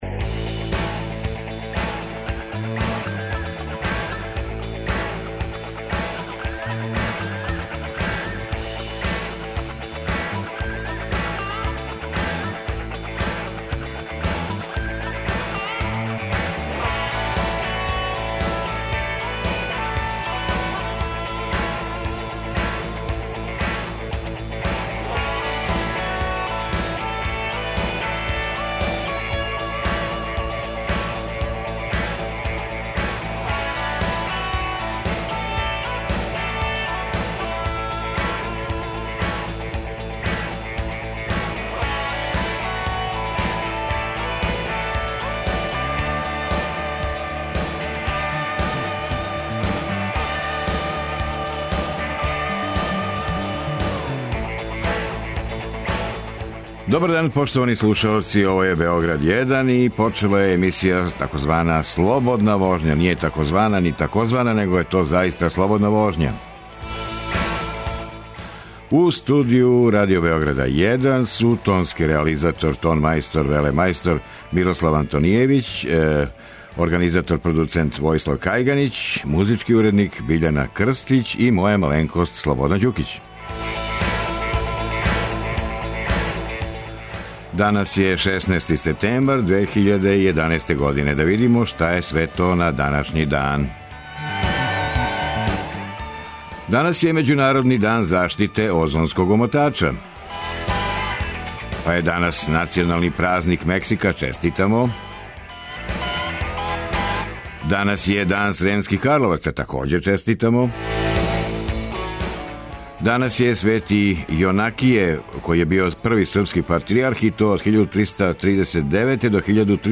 У другом сату укључићемо слушаоце који желе да са нама продискутују на тему: Да ли је умро рокенрол?